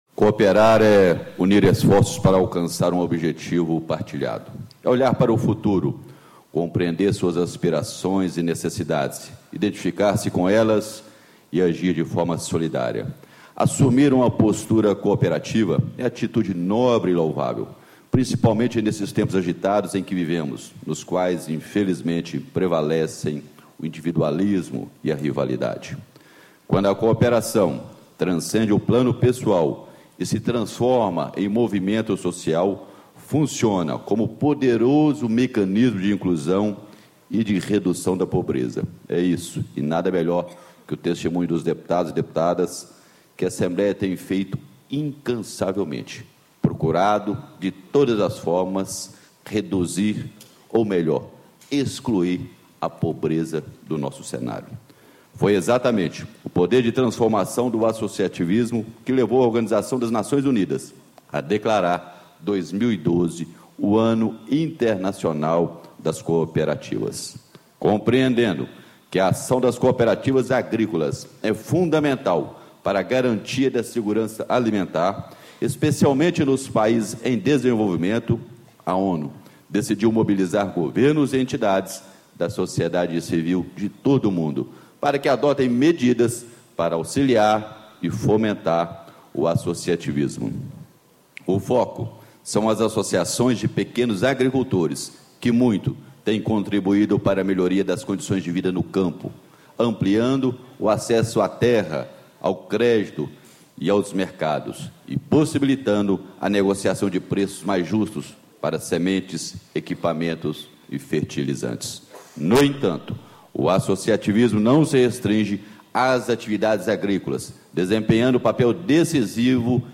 Deputado Dinis Pinheiro (PSDB), Presidente da Assembleia Legislativa de Minas. Pronunciamento de abertura